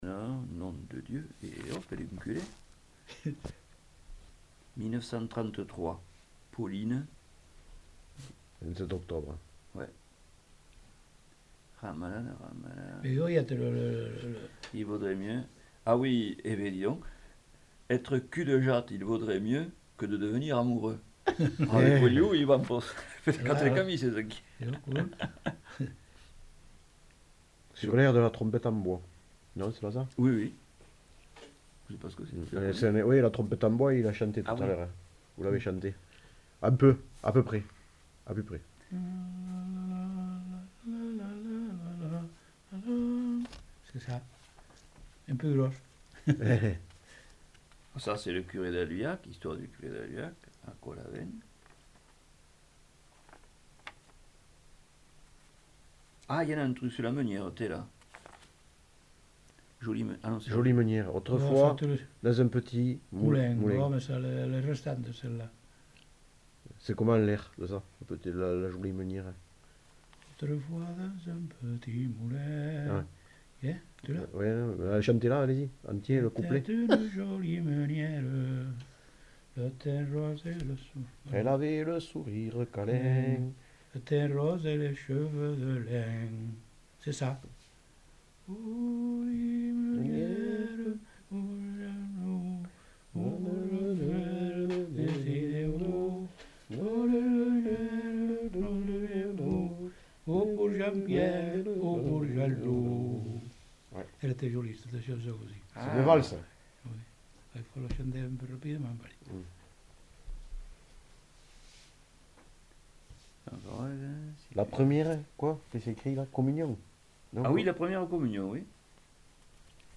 Aire culturelle : Lauragais
Genre : chant
Effectif : 1
Type de voix : voix d'homme
Production du son : chanté
Danse : valse